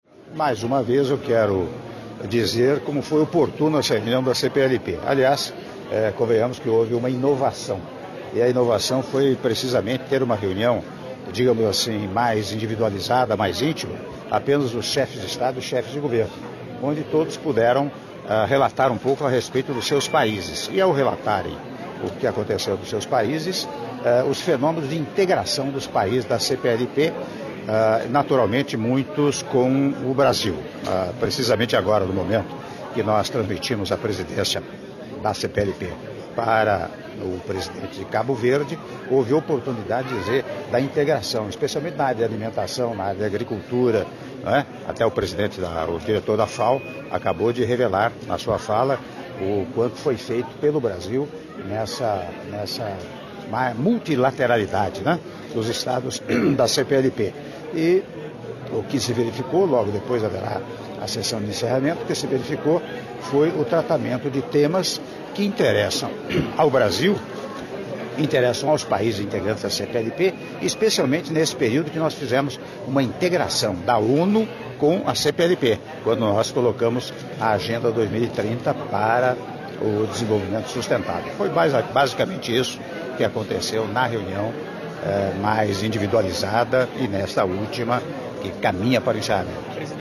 Áudio da entrevista coletiva do Presidente da República, Michel Temer, após a Sessão Plenária da Conferência de Chefes de Estado e de Governo da CPLP- Ilha do Sal/Cabo Verde- (01min30s)